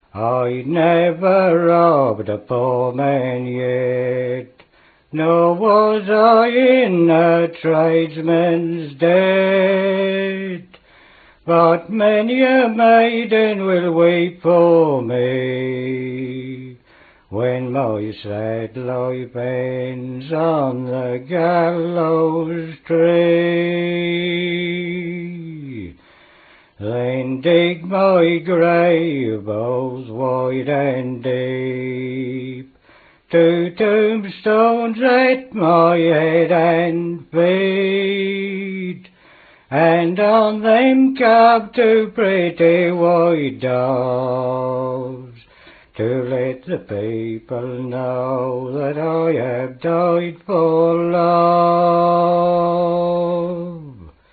Everything he does to a song is here: the extended lines; variable tune and rhythm; lengthened pauses between lines; exquisite timing; clarity, musicality ... all displayed to perfection, and all subjugated to the supreme task of telling the story.